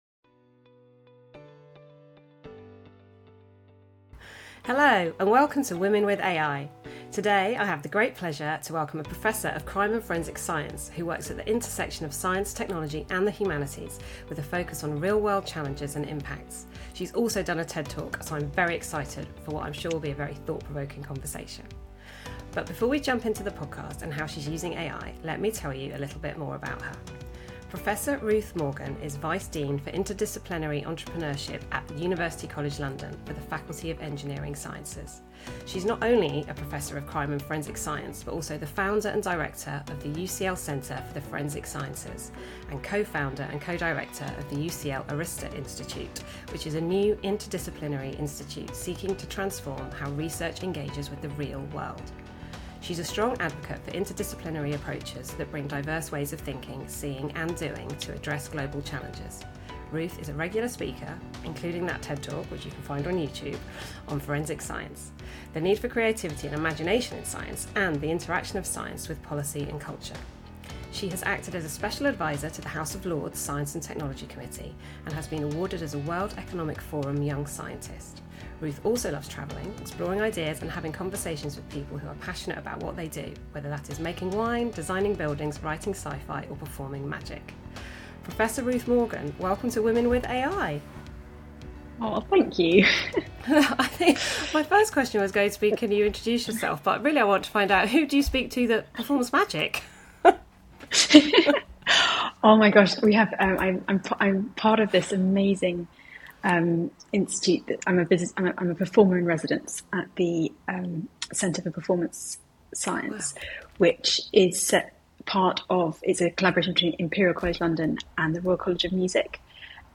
AI and Forensic Science: A Conversation